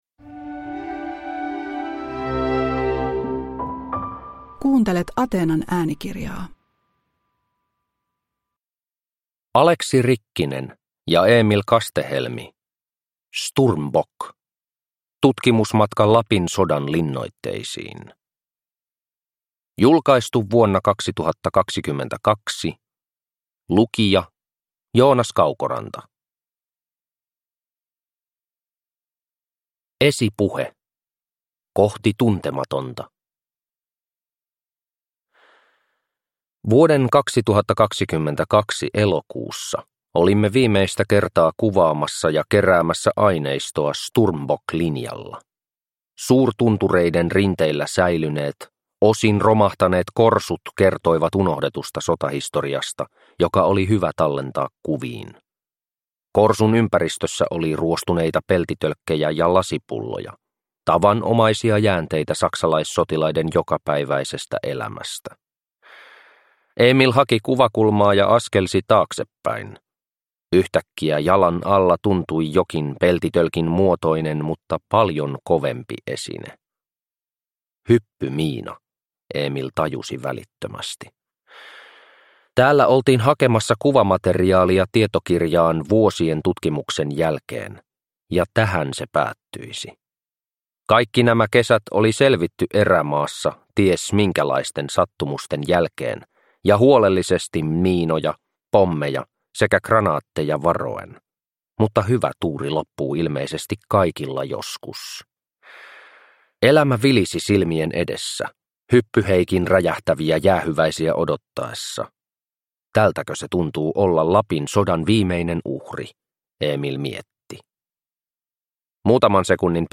Sturmbock – Ljudbok – Laddas ner